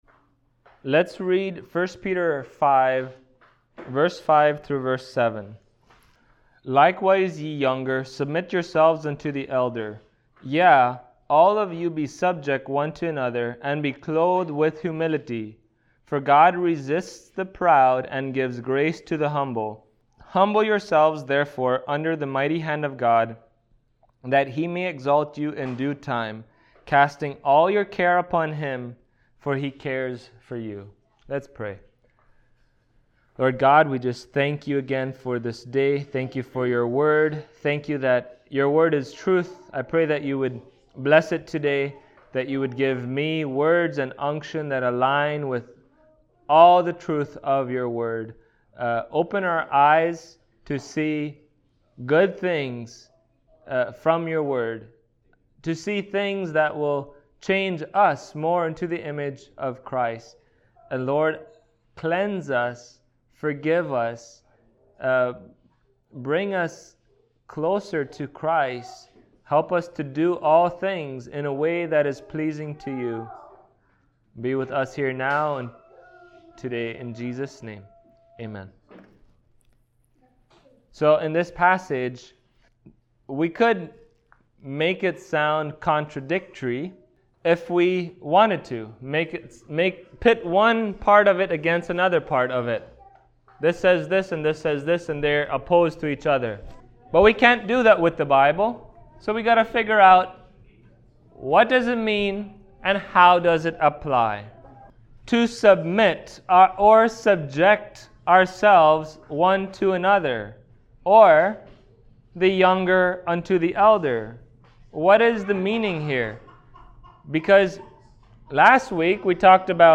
Passage: 1 Peter 5:5-7 Service Type: Sunday Morning